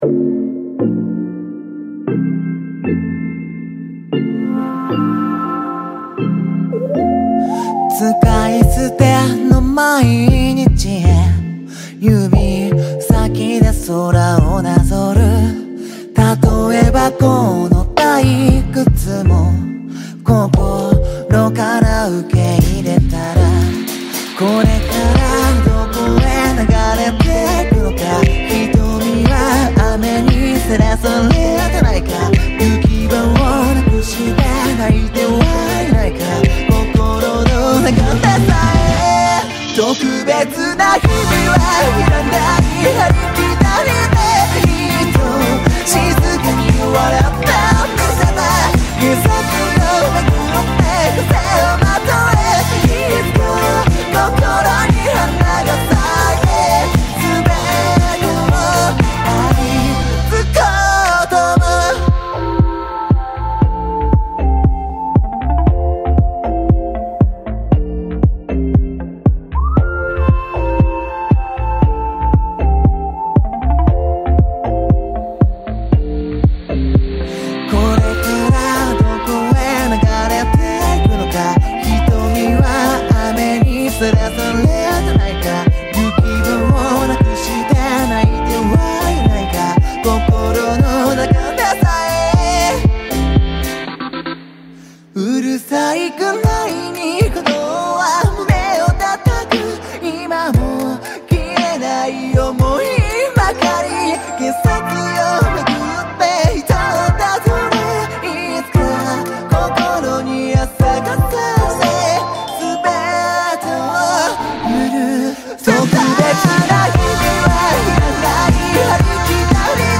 BPM59-117
Audio QualityCut From Video